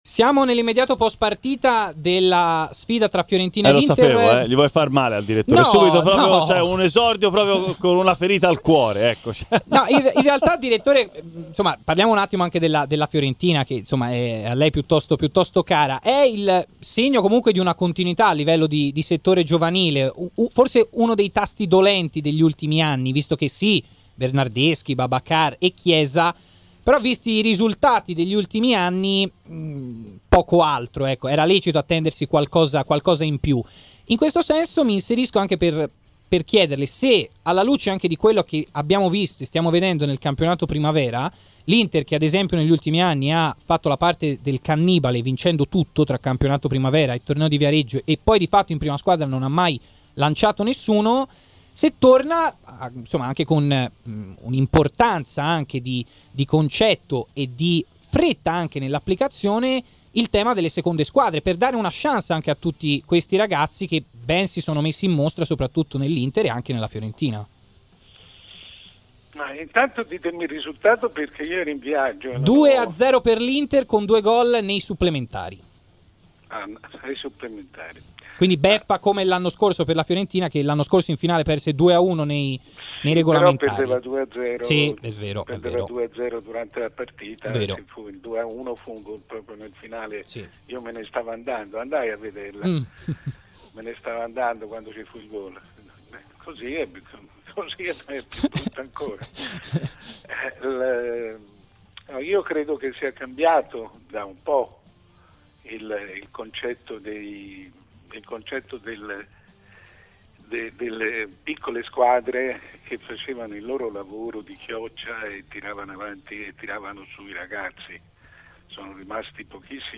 Appuntamento fisso nel sabato sera del Live Show di RMC Sport con il direttore Mario Sconcerti, che in diretta analizza i temi di giornata: